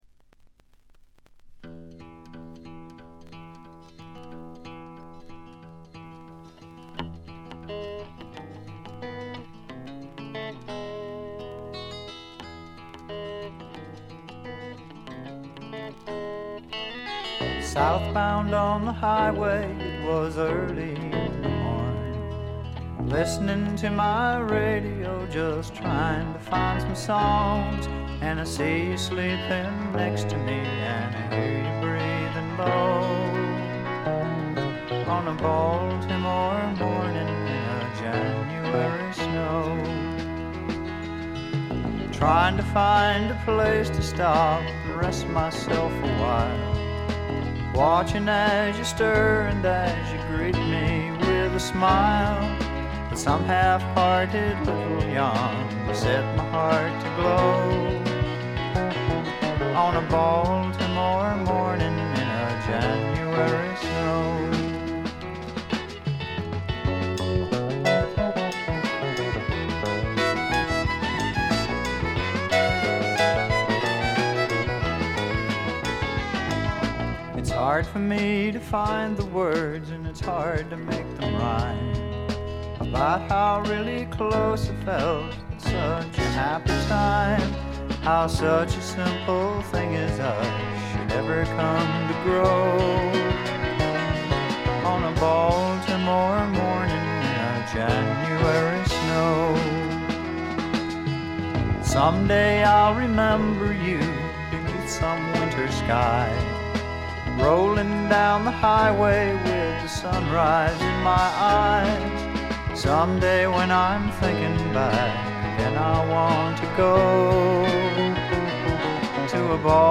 静音部で軽微なチリプチ少々。
試聴曲は現品からの取り込み音源です。